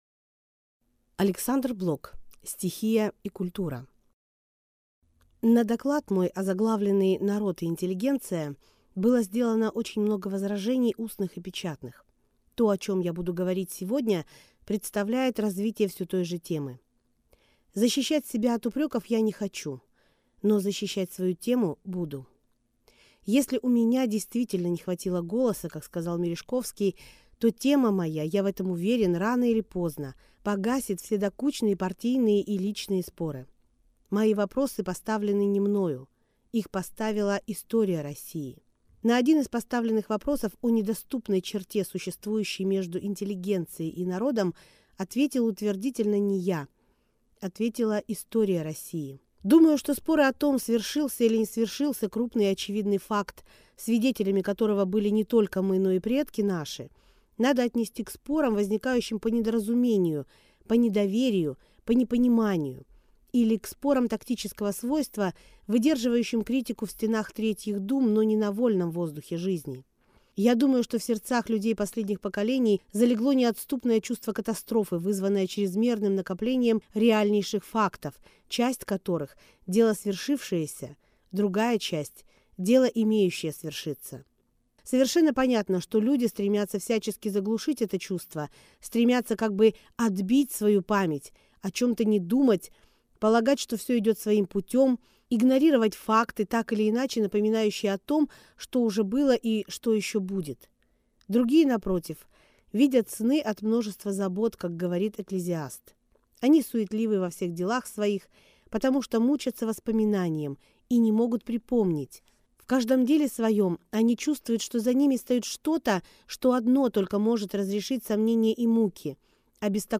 Аудиокнига Стихия и культура | Библиотека аудиокниг